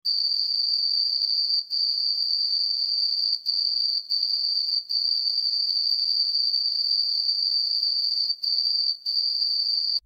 Crickets
Crickets.mp3